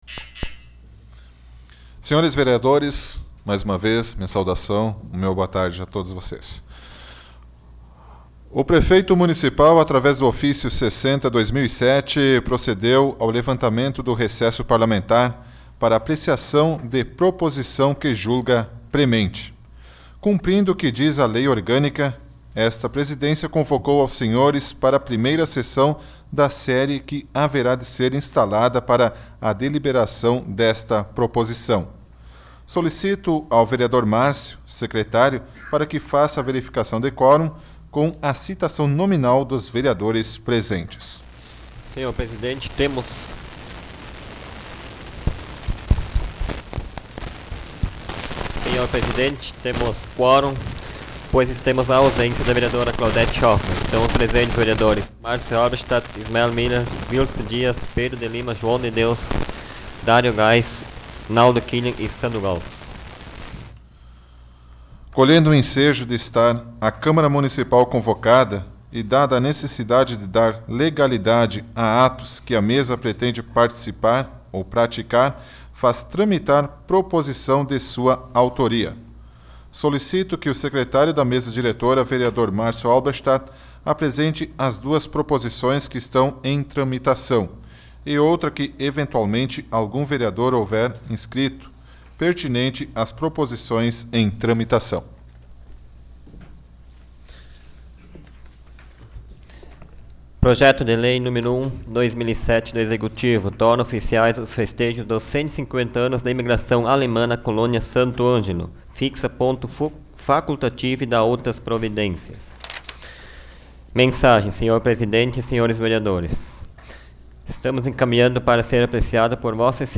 Áudio da 32ª Sessão Plenária Extraordinária da 12ª Legislatura, de 08 de fevereiro de 2007